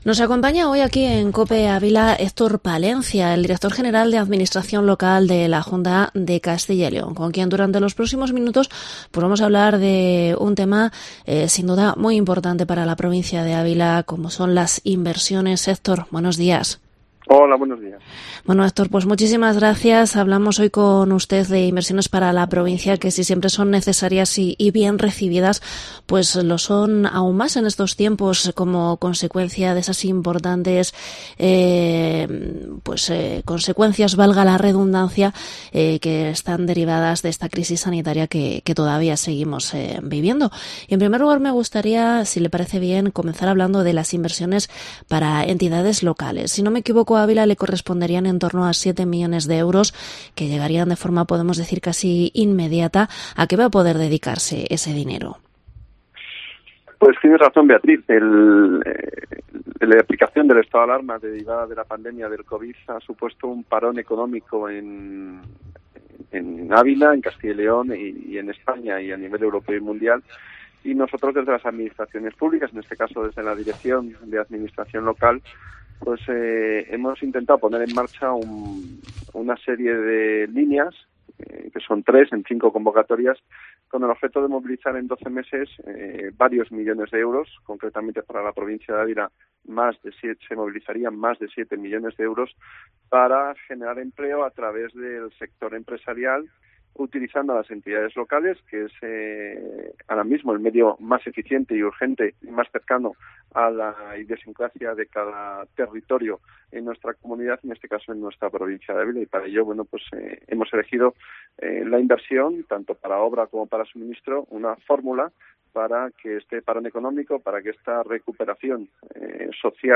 Entrevista al director general de Administración Local de la Junta de Castilla y León, Héctor Palencia